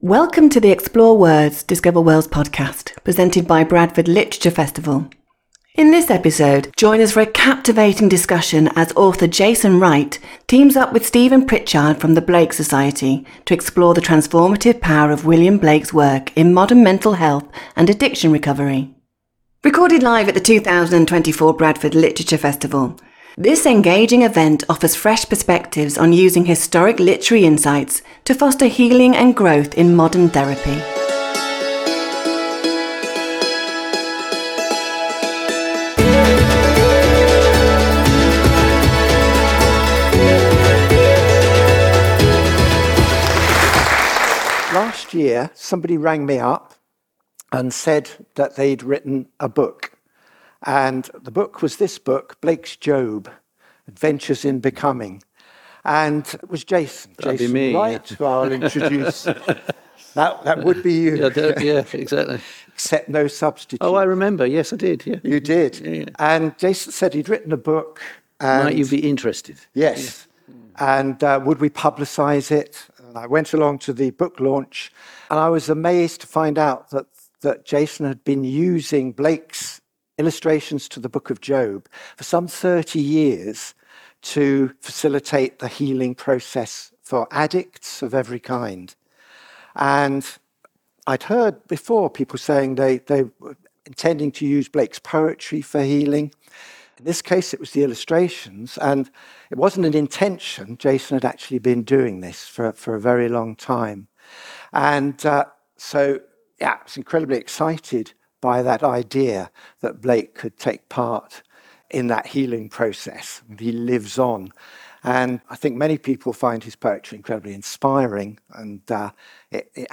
Whether you are a political enthusiast, a student of international relations or simply curious about global democratic processes, this panel will offer valuable insights and stimulating discussion.